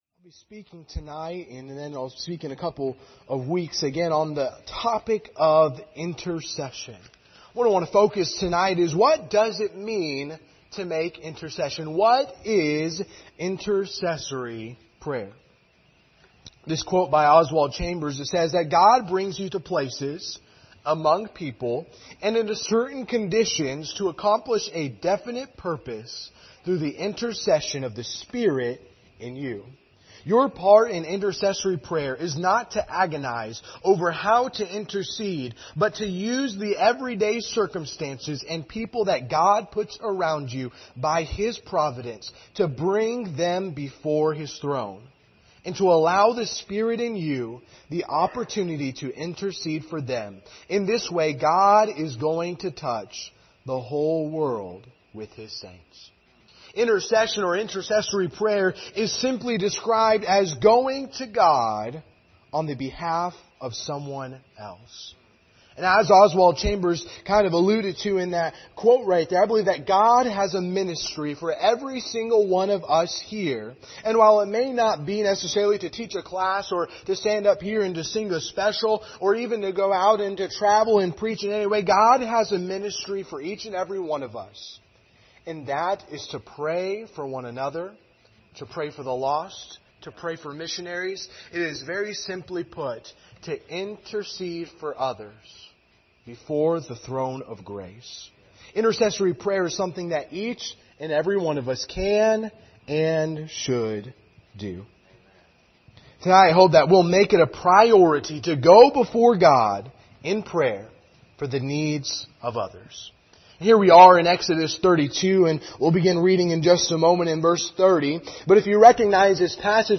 General Passage: Exodus 32:30-34 Service Type: Sunday Evening Topics